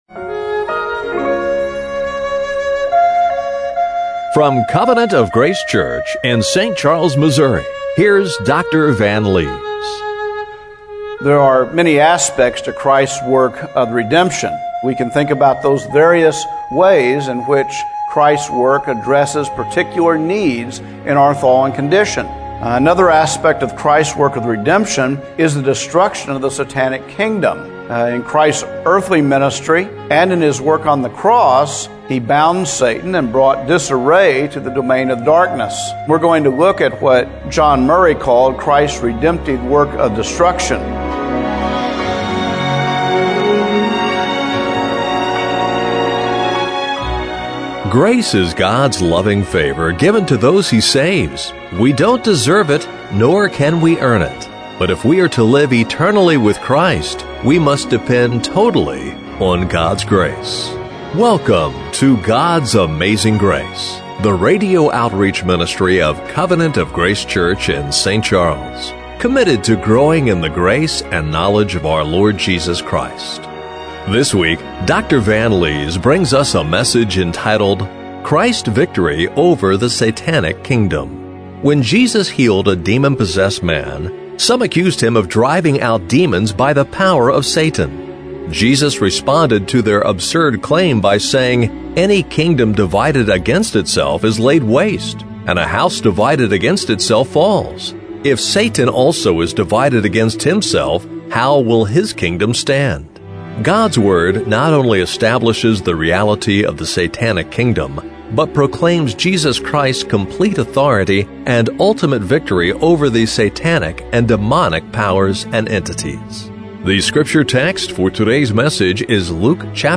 Luke 11:14-22 Service Type: Radio Broadcast What does God's Word teach us about Jesus' authority over demonic powers and entities?